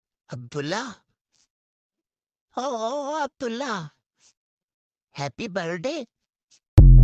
Goat calling the name of sound effects free download